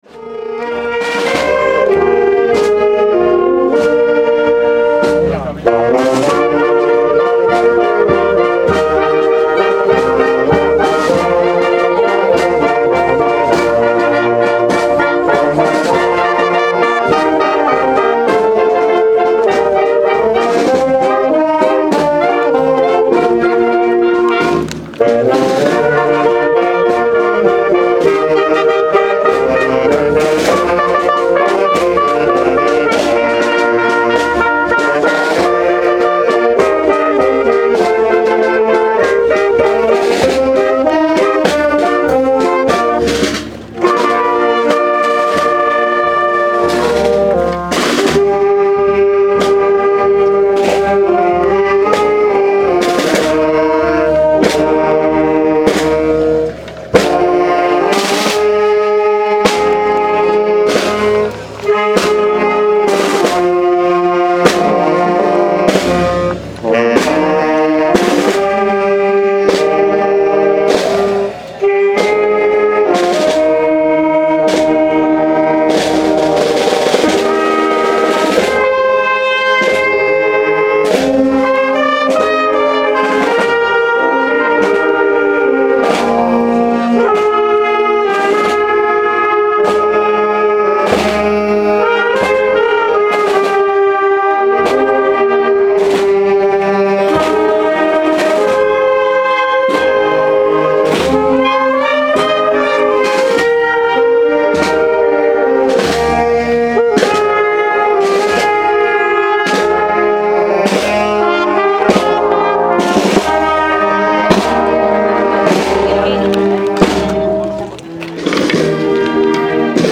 Procesión de La Huerta 2014
El pasado sábado 6 de septiembre tuvo lugar la procesión en honor la Virgen de Las Huertas, acompañada por vecinos, autoridades municipales y religiosas, y la Banda de Música de la Agrupación Musical de Totana.